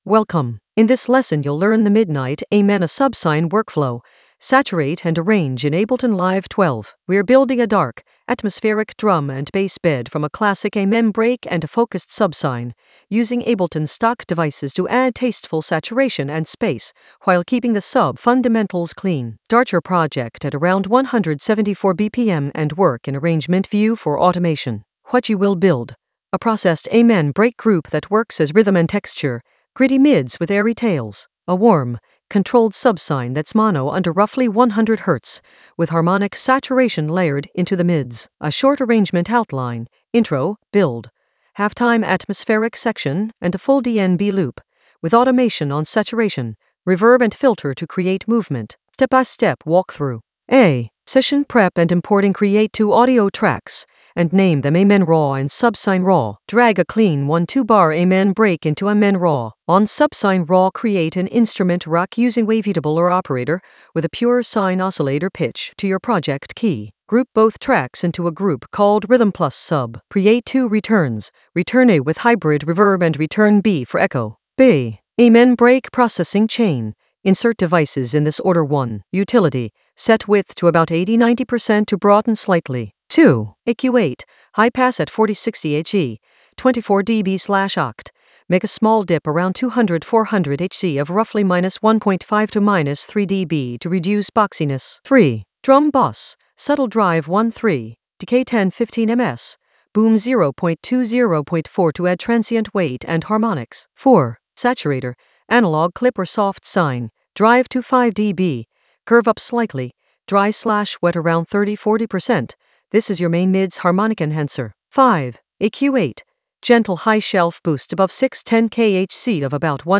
Narrated lesson audio
The voice track includes the tutorial plus extra teacher commentary.
An AI-generated intermediate Ableton lesson focused on Midnight Amen a subsine workflow: saturate and arrange in Ableton Live 12 in the Atmospheres area of drum and bass production.